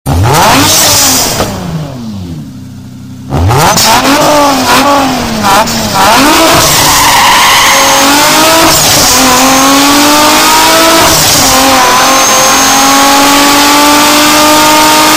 engine-sound_24832.mp3